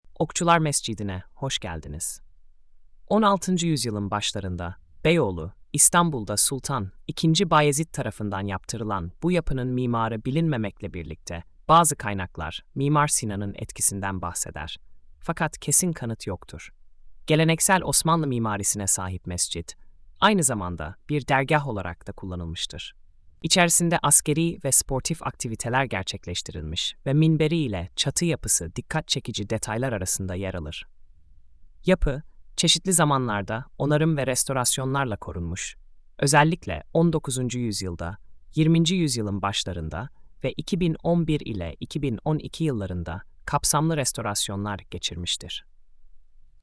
Sesli Anlatım: